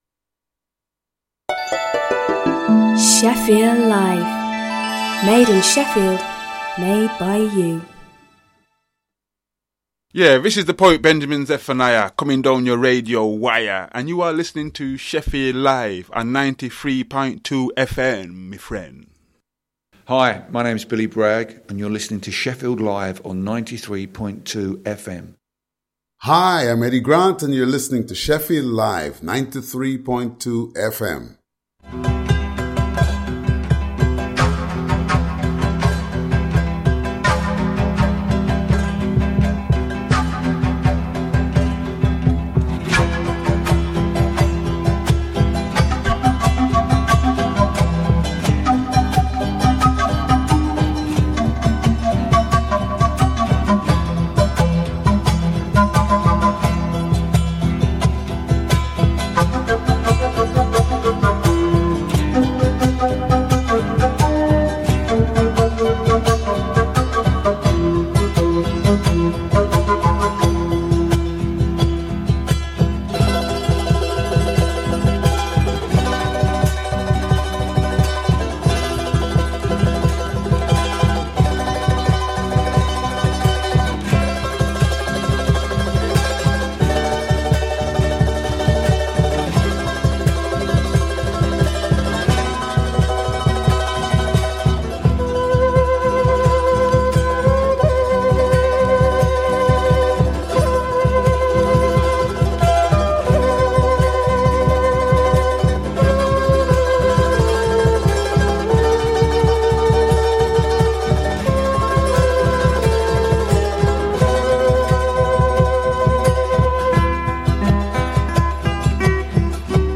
A programme that introduces all different styles of Latin music from its roots and at the same time inform the audience of the latest issues in Latin America, also interviews with artists visiting the city (not necessarily South American).